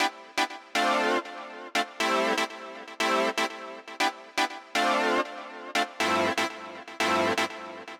23 ChordSynth PT2.wav